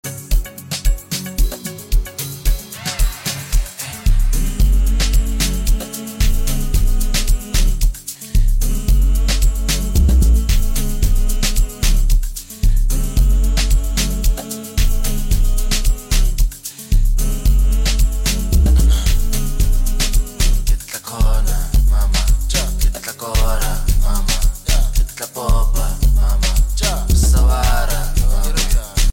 the phenomenon African singer, songwriter, and producer